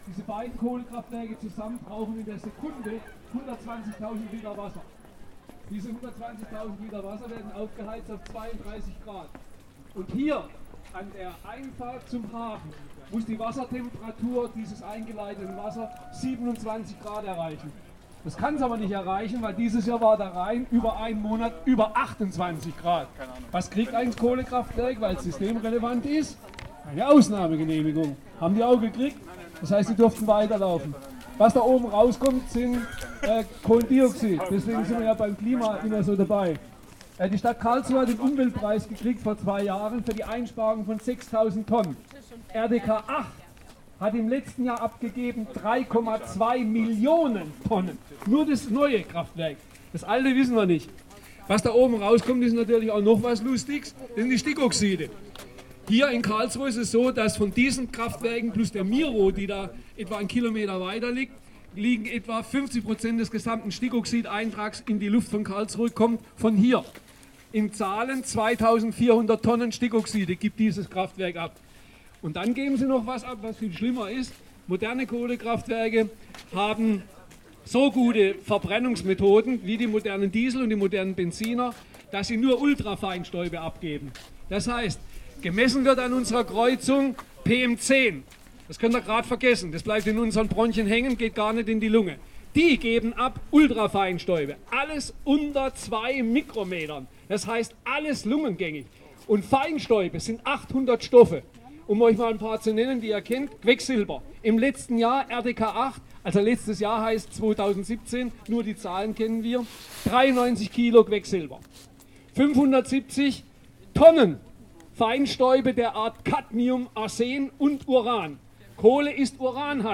In Freiburg demonstrierten am Donnerstagnachmittag rund 60 menschen gegen die Repression gegen Klimaaktivist*innen. RDL war vor Ort und begleitete die Kundgebung: 5:04